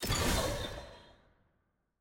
sfx-jfe-ui-generic-rewards-click.ogg